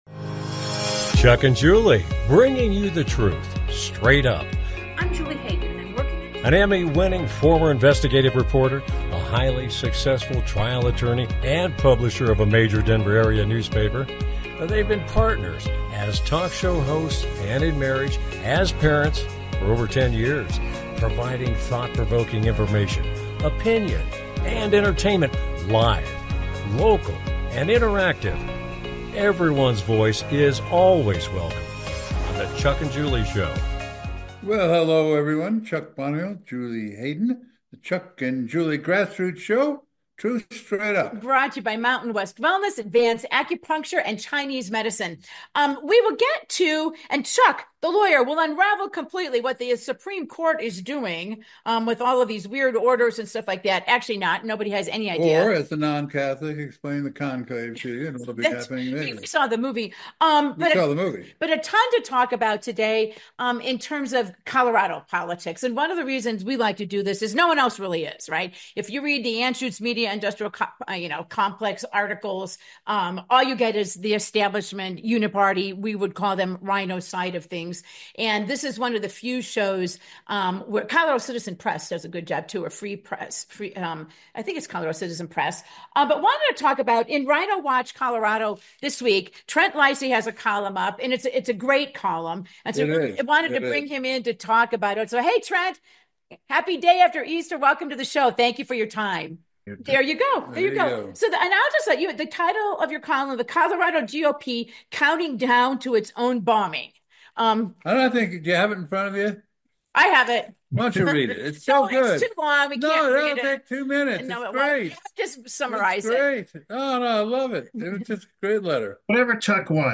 Their program is a live Internet call-in talk show providing thought provoking information, conversation and entertainment. They are dedicated to free speech and critical thinking and any and all opinions are welcome.